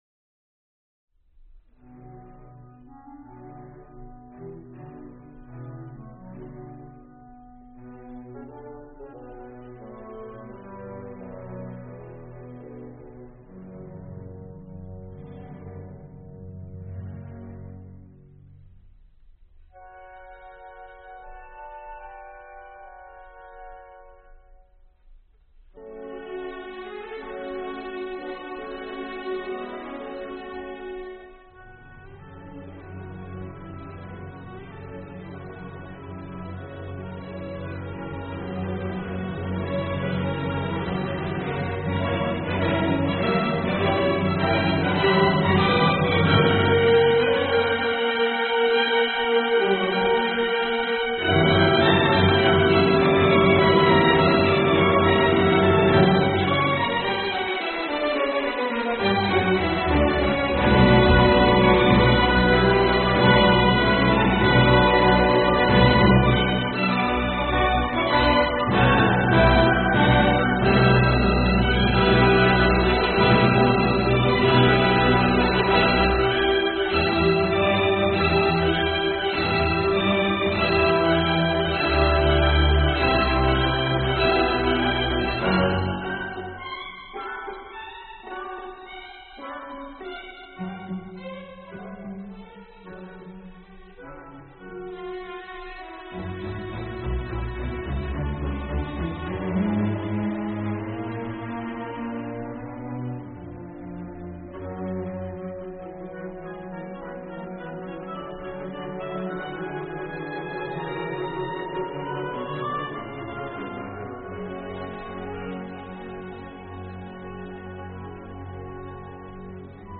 古典音樂
出神入化的獨奏技巧，散發出豐美而又詳制的熱情。